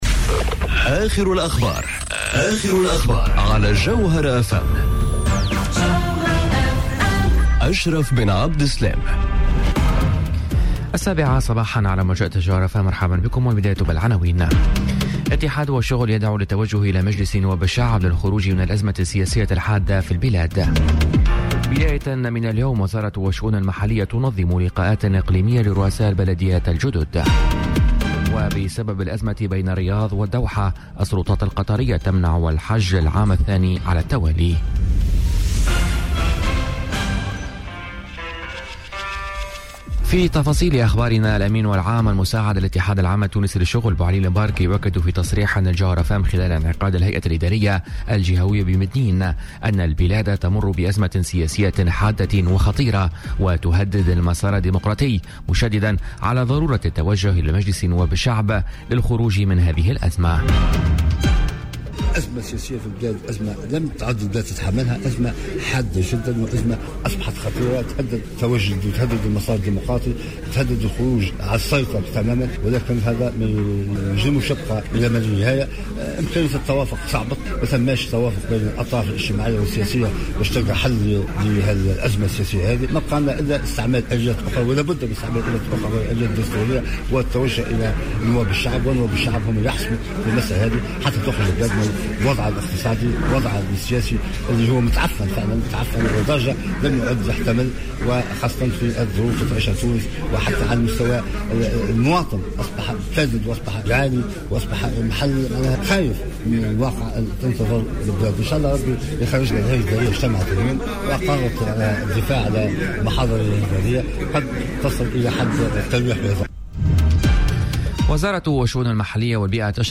نشرة أخبار السابعة صباحا ليوم الإثنين 23 جويلية 2018